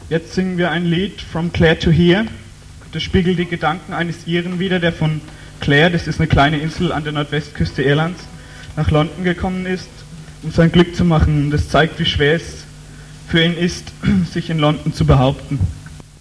08 - Ansage.mp3